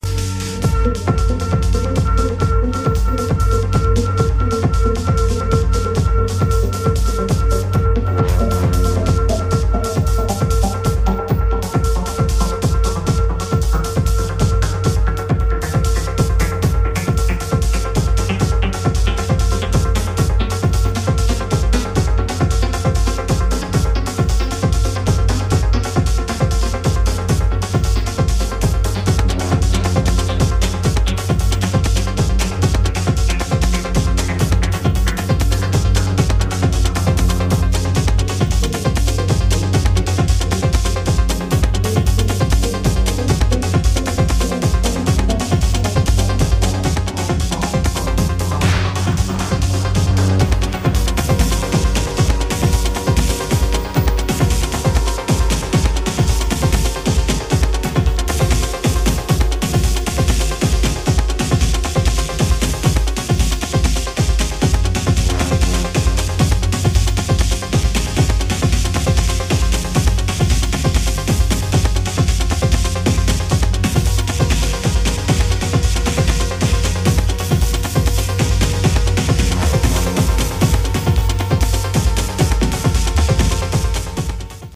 strictly underground & classic Techno tracks
Dub techno